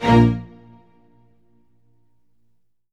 ORCHHIT A01L.wav